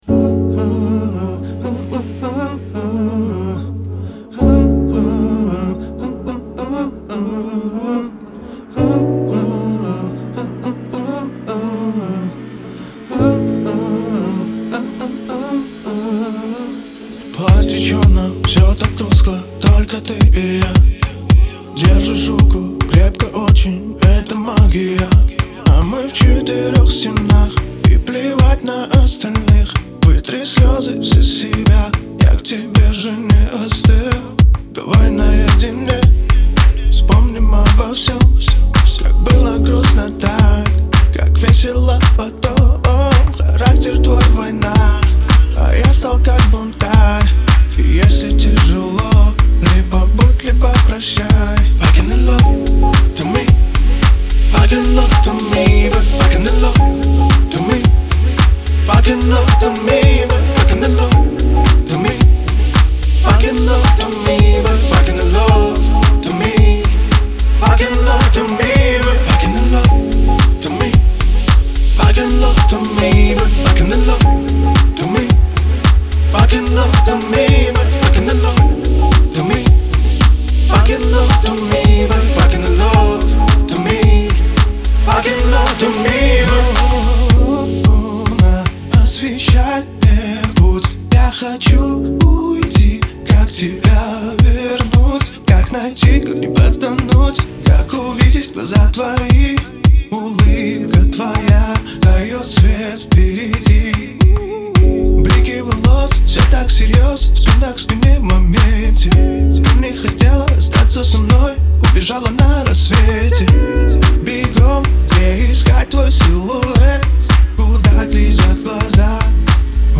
это энергичная композиция в жанре поп с элементами R&B
выразительным вокалом